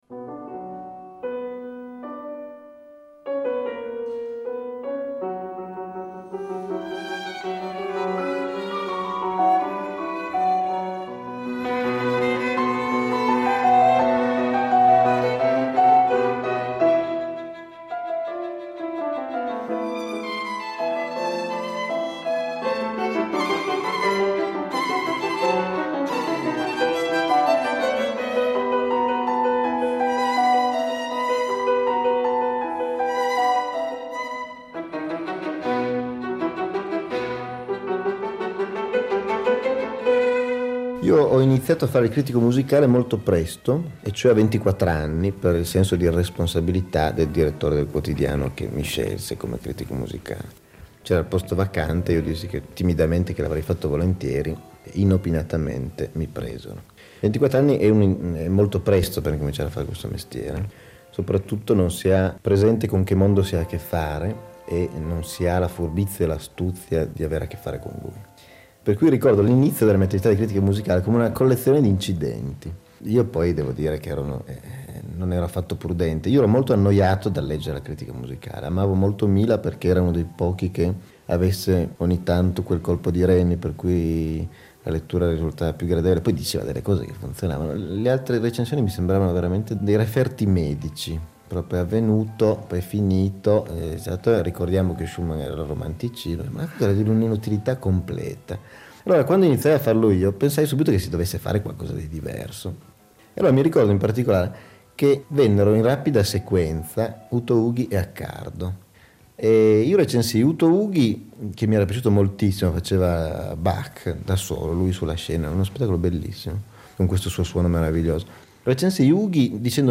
Il noto scrittore, drammaturgo, sceneggiatore, autore televisivo, critico musicale, conduttore televisivo torinese Alessandro Baricco parla a ruota libera di una delle sue grandi passioni, la musica classica e, in particolare, quella operistica.